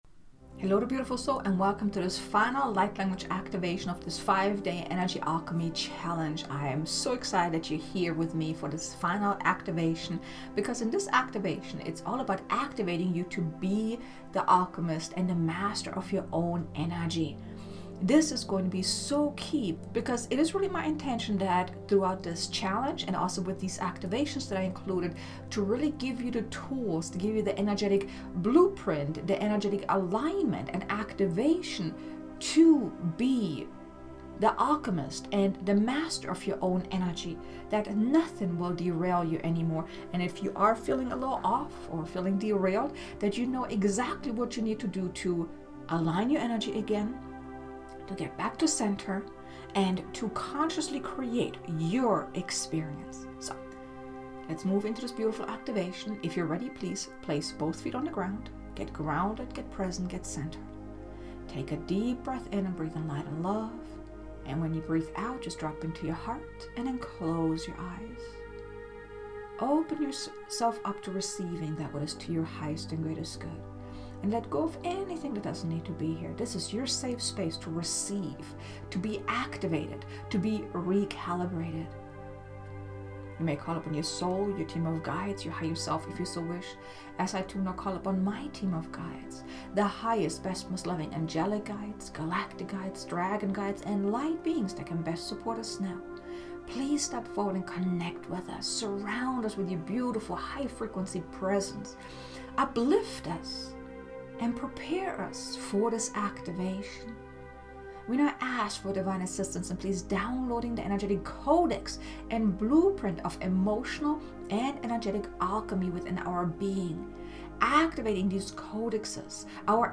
Light Language Activation: Activation to BE the Alchemist and Master of own Energy